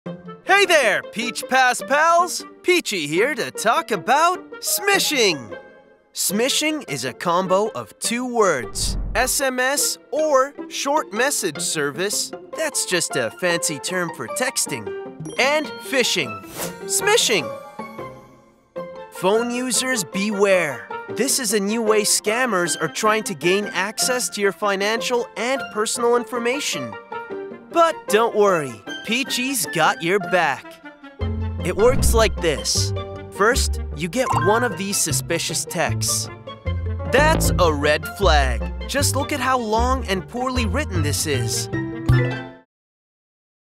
Male
Yng Adult (18-29), Adult (30-50)
My voice is filled with ENERGY and I can do reads for any voice over you might need.
E-Learning